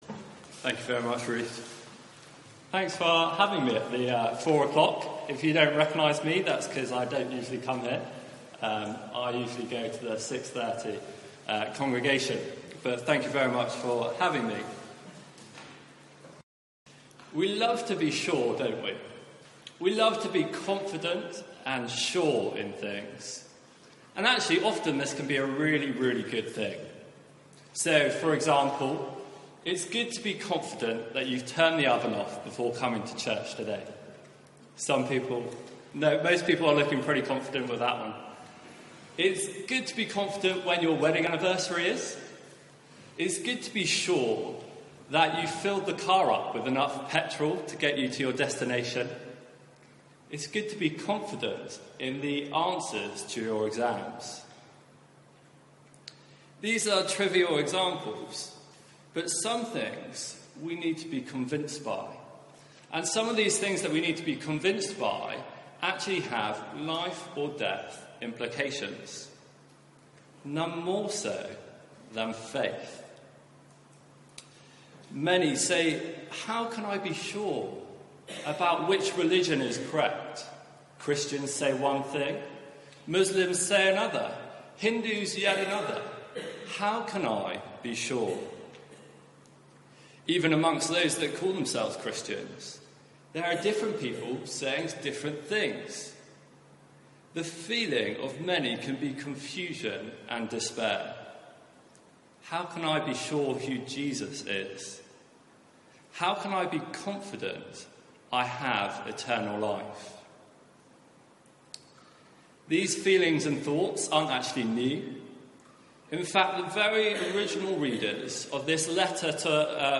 Media for 4pm Service
Theme: Eternal Life achieved for you Sermon